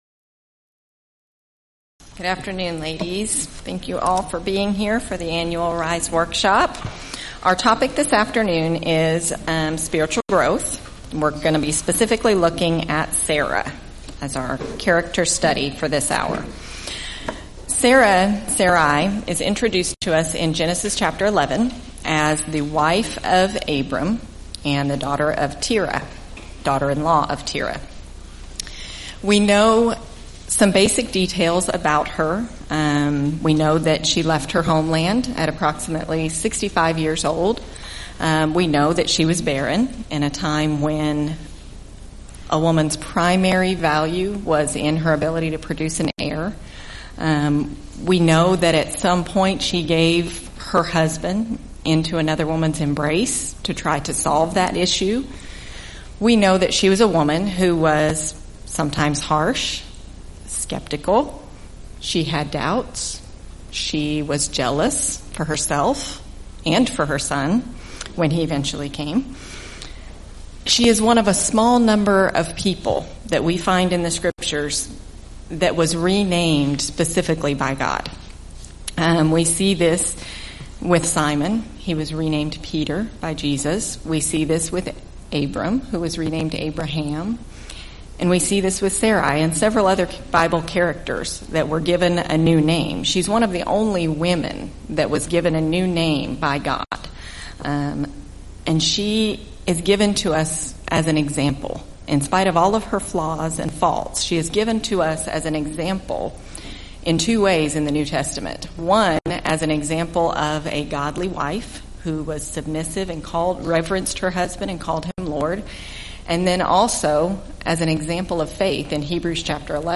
Event: 2nd Annual Arise Workshop
Ladies Sessions